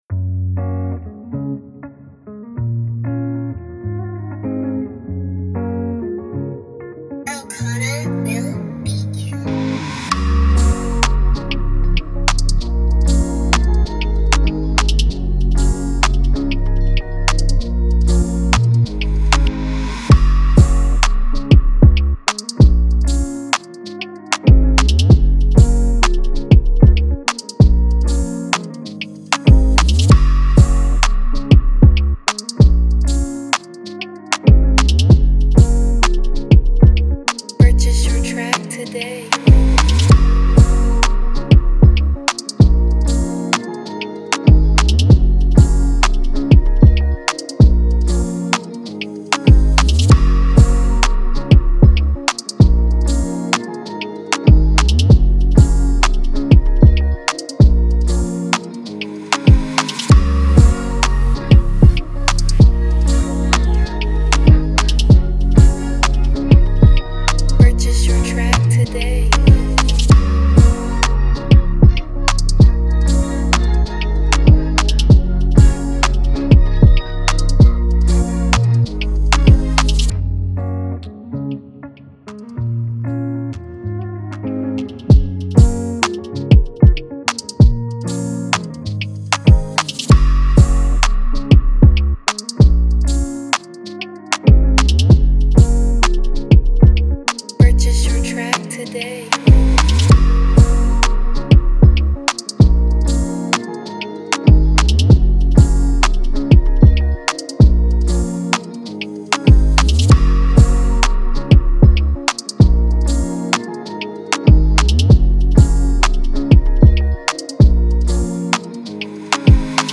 At 96 BPM
With its infectious rhythm and captivating melodies